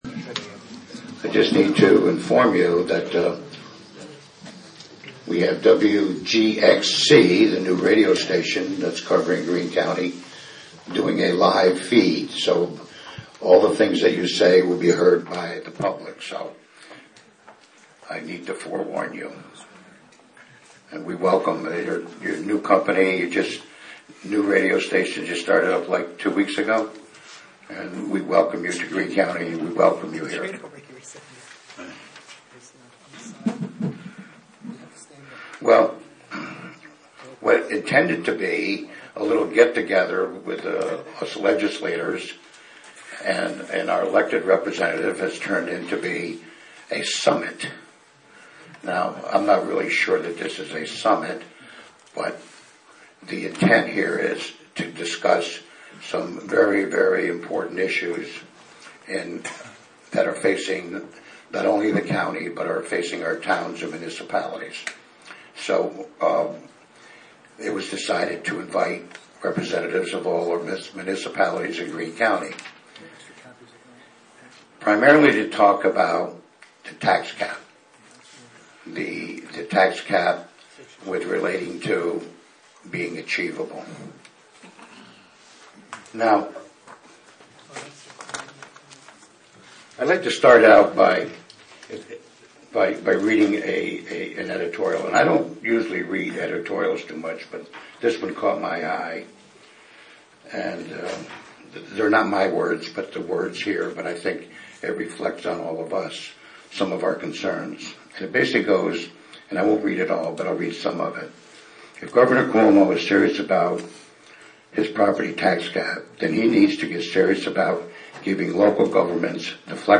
Greene County fiscal meeting in Cairo. (Audio)
Meeting of Greene Counties' state representatives, legislators, town supervisors, and village mayors to discuss the current budget crisis, and proposals to the state budget that may impact Greene County.